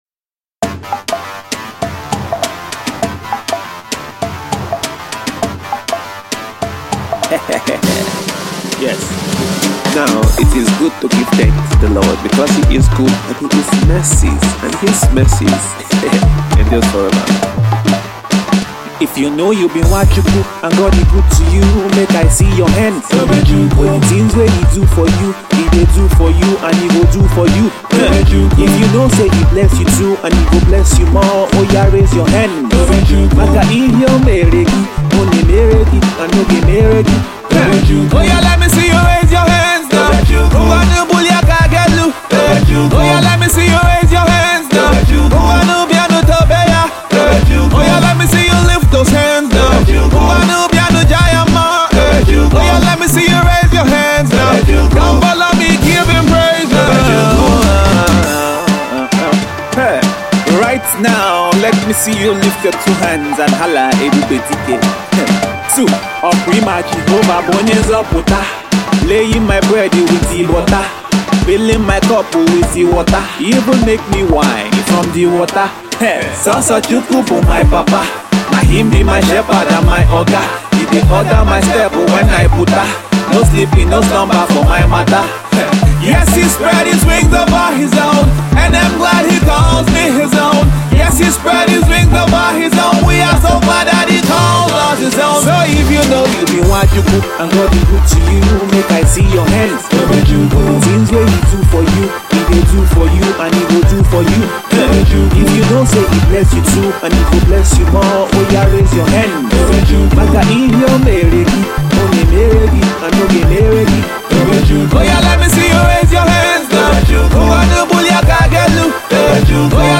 smashing afro single
rap bars